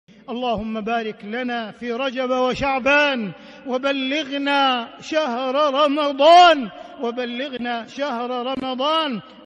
CategoryRamadan - Dua
Event / TimeAfter Isha Prayer